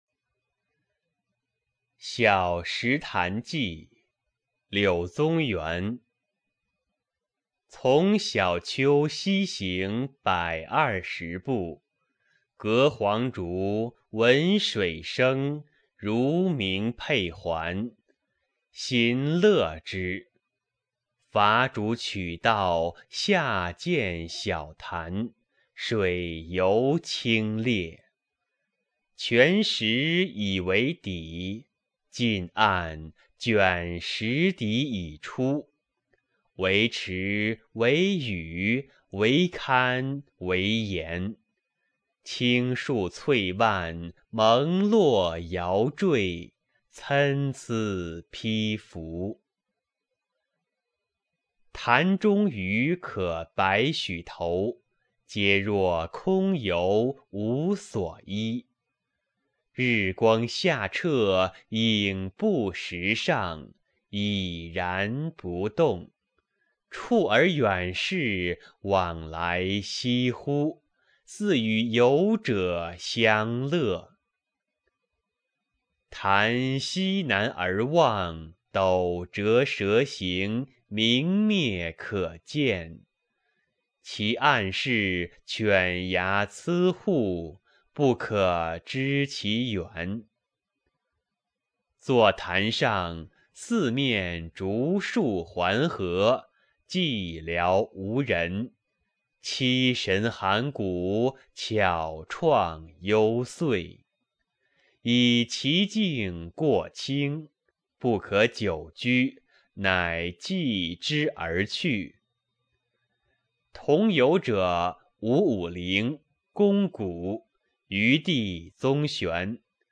《小石潭记》课文朗读